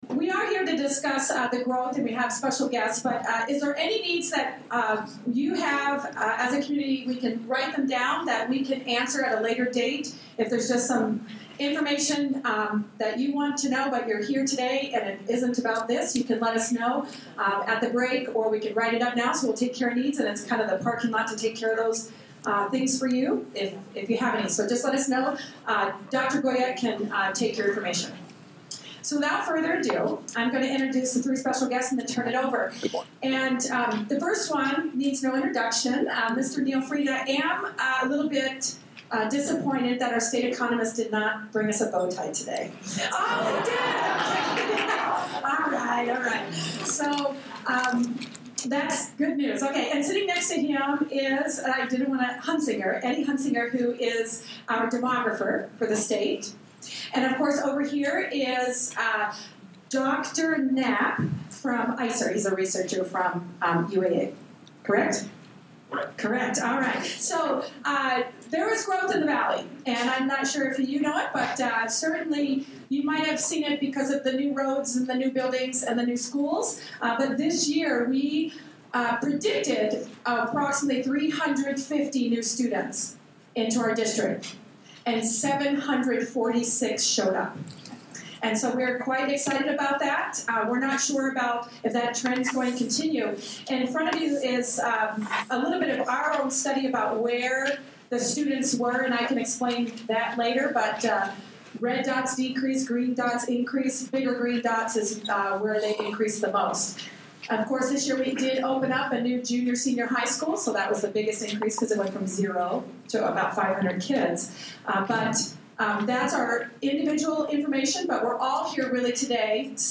Due to a technical problem the audio is of a lesser quality than usual.